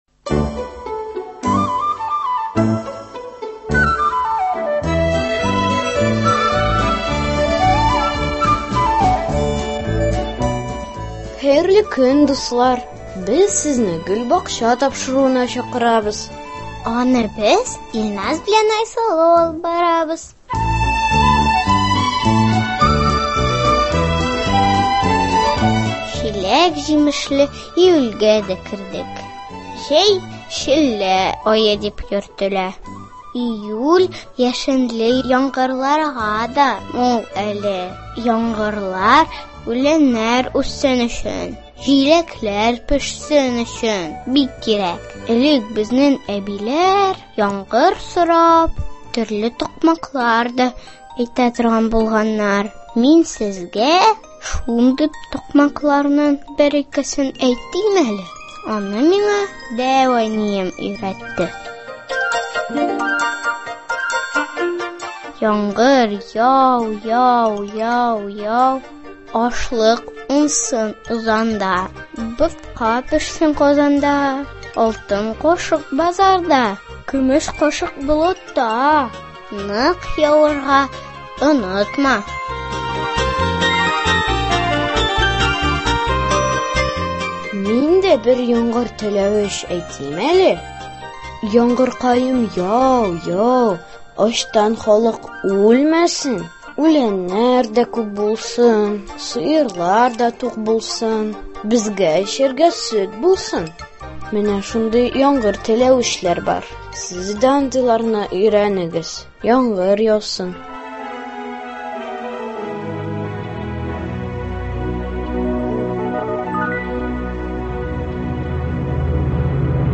Яңгыр теләвечләр, матур сүзләр әйтеп әкиятләр илендә дә сәяхәт итәрбез. Нәни дусларыбыз да үз чыгышлары белән сөендерер.